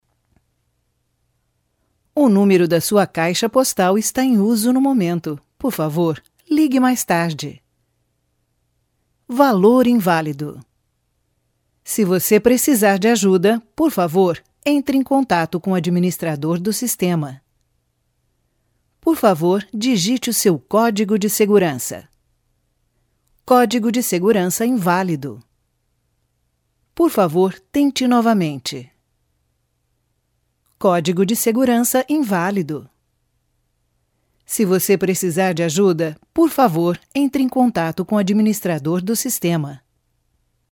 葡萄牙语翻译团队成员主要由中国籍和葡萄牙语国家的中葡母语译员组成，可以提供证件类翻译（例如，驾照翻译、出生证翻译、房产证翻译，学位证翻译，毕业证翻译、成绩单翻译、无犯罪记录翻译、营业执照翻译、结婚证翻译、离婚证翻译、户口本翻译、奖状翻译等）、公证书翻译、病历翻译、葡语视频翻译（听译）、葡语语音文件翻译（听译）、技术文件翻译、工程文件翻译、合同翻译、审计报告翻译等；葡萄牙语配音团队由葡萄牙语国家的葡萄牙语母语配音员组成，可以提供葡萄牙语专题配音、葡萄牙语广告配音、葡萄牙语教材配音、葡萄牙语电子读物配音、葡萄牙语产品资料配音、葡萄牙语宣传片配音、葡萄牙语彩铃配音等。
葡萄牙语样音试听下载